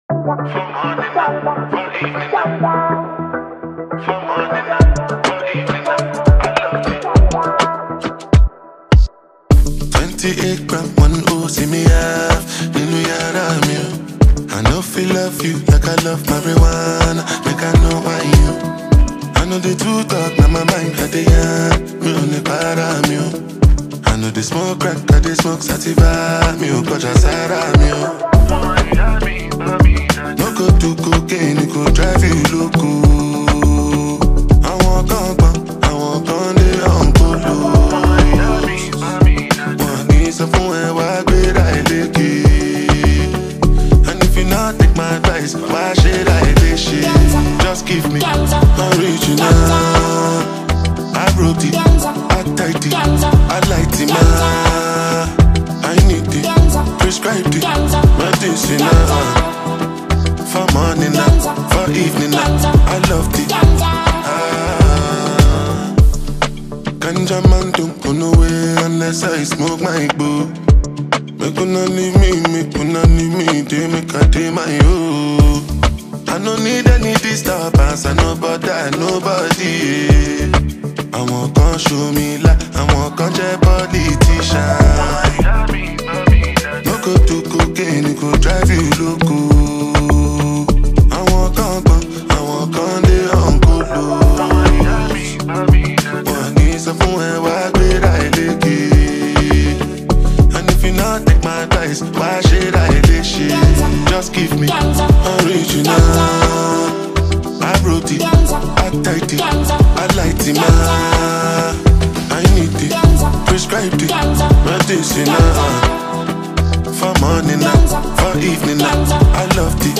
a formidable Afrobeat Nigerian singer